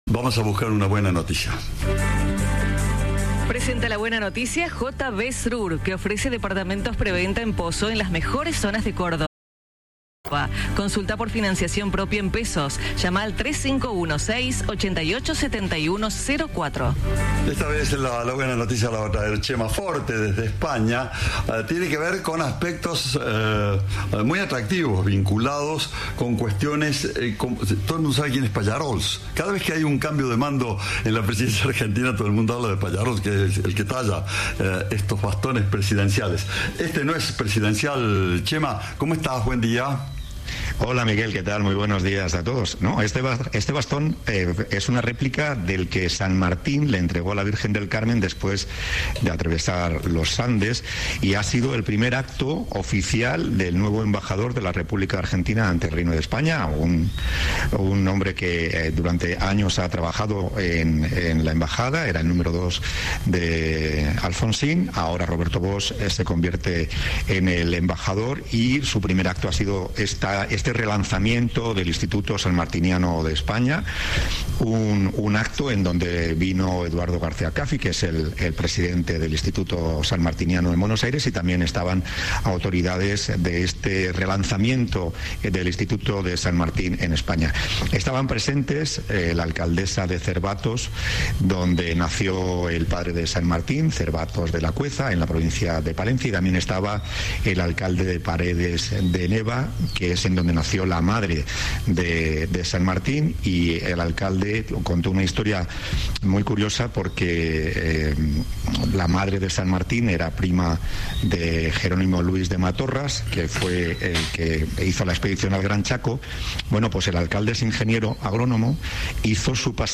Informe